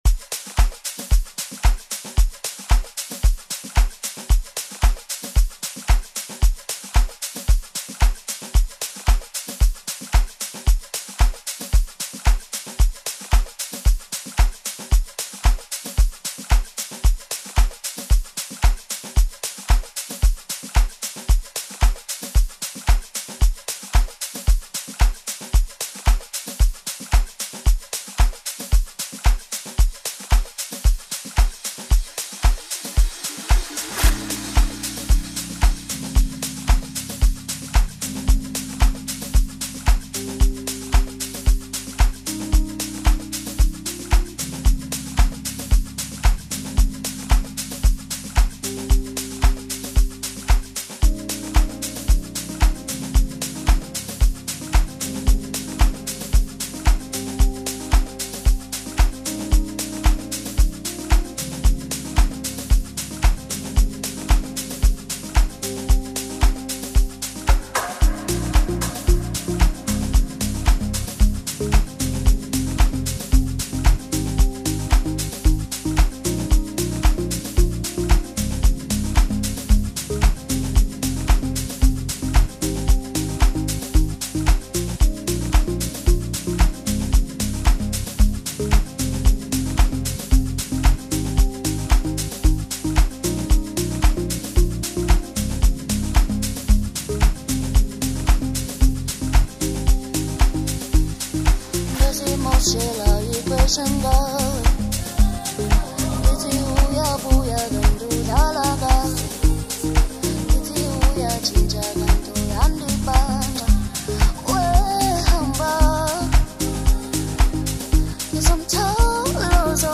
Amapiano song